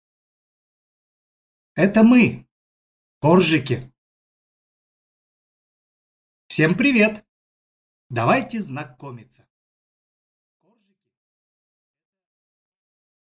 Aудиокнига Это мы – Коржики!